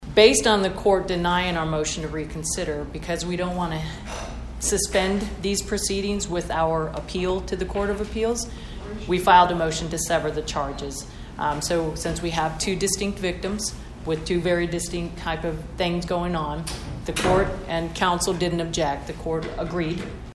Pottawatomie County Attorney Sherri Schuck explained the prosecution’s next step, which includes an appeal to the Kansas Court of Appeals.